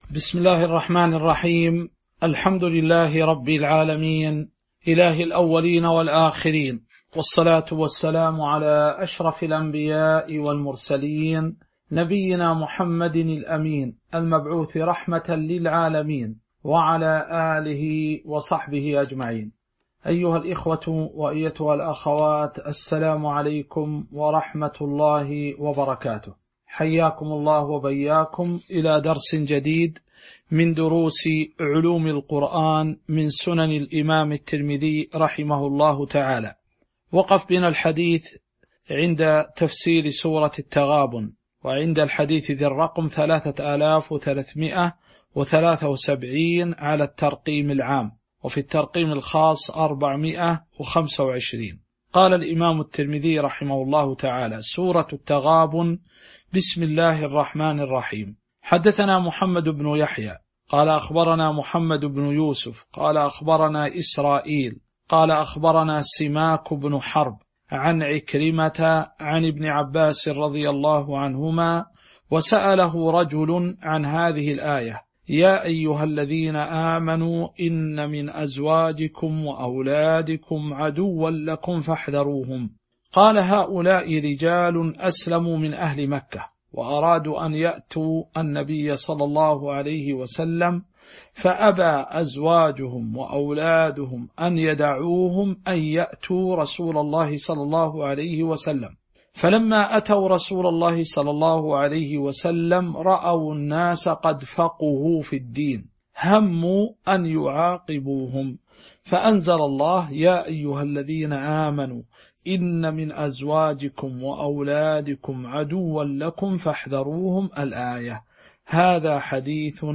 تاريخ النشر ٢٦ رجب ١٤٤٣ هـ المكان: المسجد النبوي الشيخ